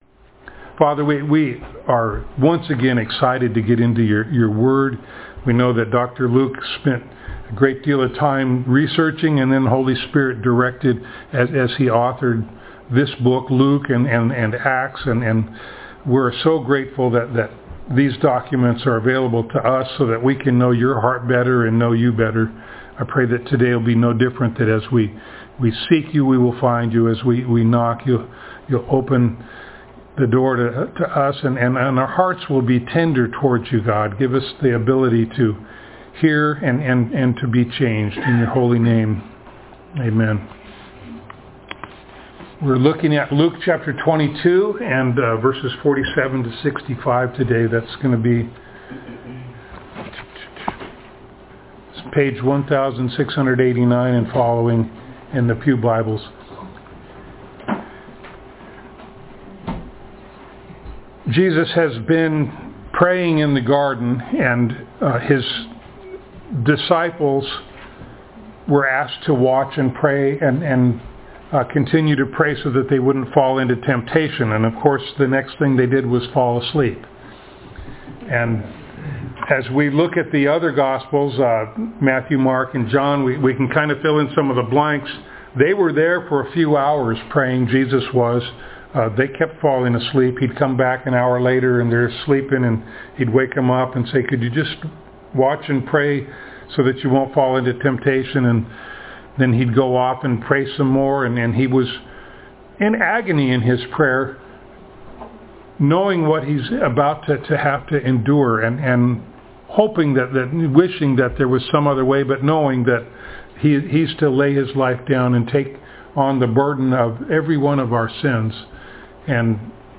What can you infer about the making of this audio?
Passage: Luke 22:47-65 Service Type: Sunday Morning Download Files Notes « Prayer